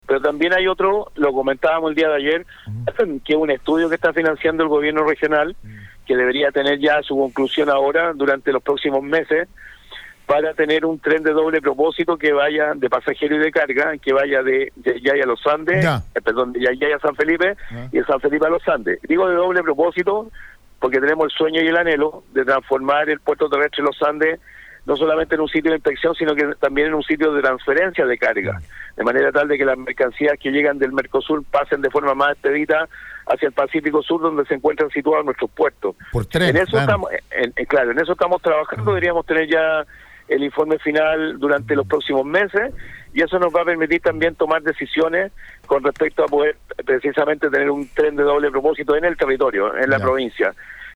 El Gobernador Regional Rodrigo Mundaca, entrevistado este jueves en Radio Aconcagua, apoyó la petición del Alcalde González.